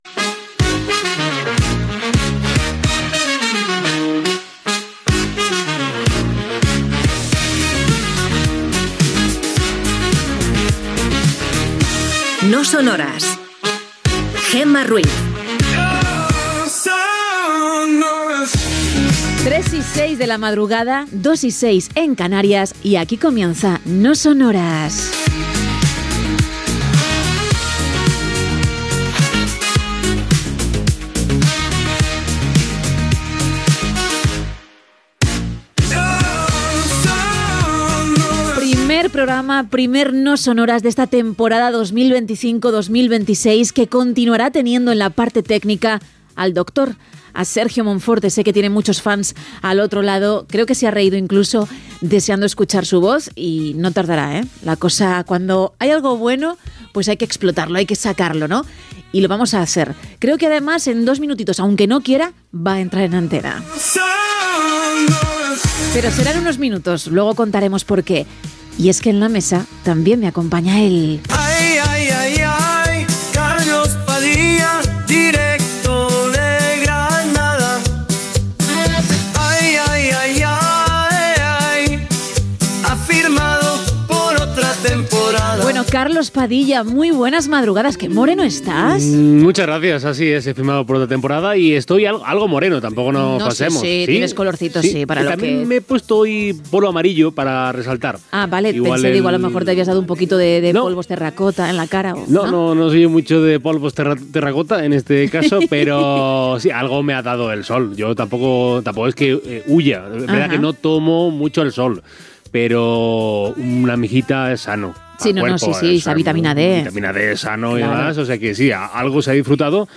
Careta, hora, equip, presentació i diàleg amb els col·laboradors del programa.
Entreteniment